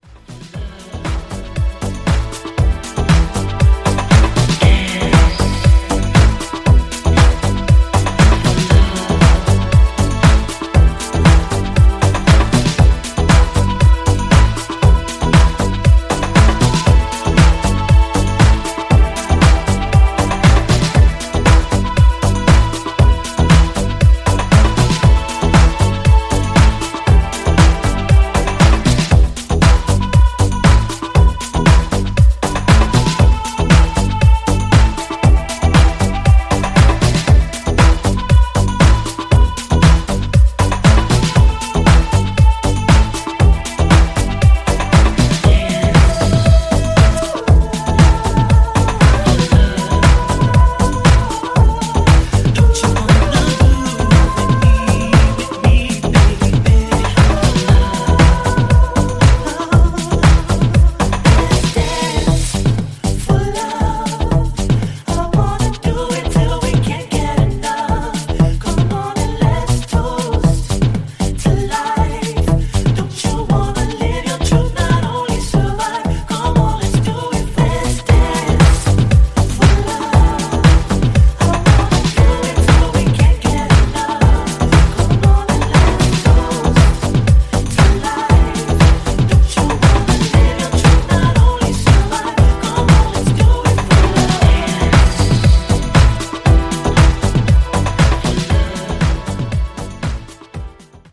> HOUSE・TECHNO